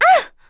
Juno-Vox_Damage_01.wav